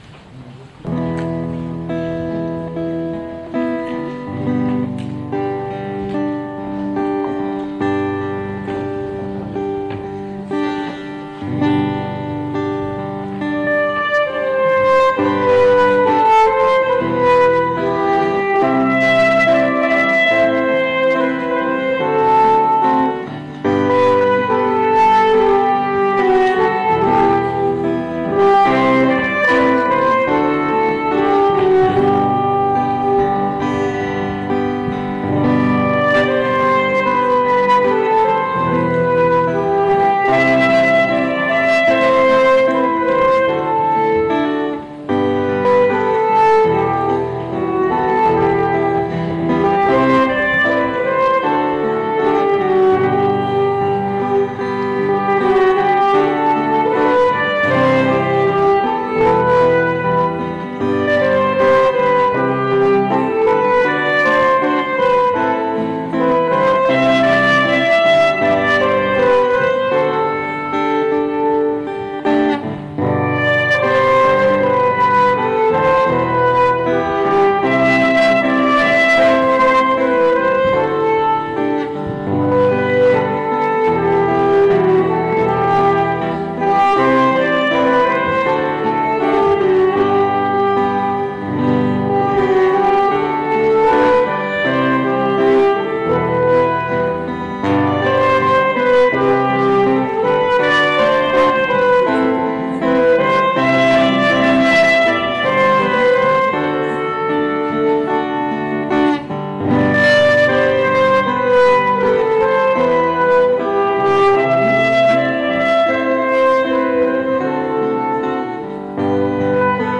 CELEBRATION D’ENTREE DANS LE JUBILE DE LA MISERICORDE
Sanctuaire du Saint Enfant Jésus de Beaune
Chants de communion :